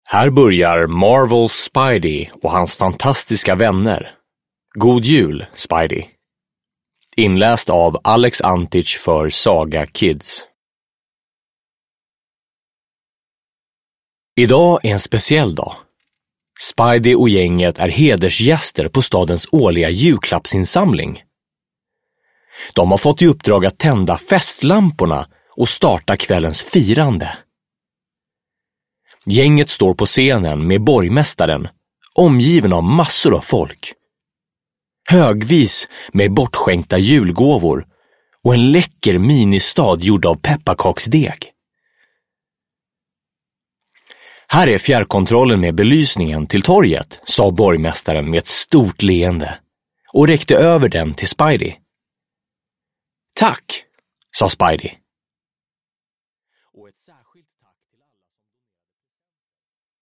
Spidey och hans fantastiska vänner – God jul, Spidey (ljudbok) av Marvel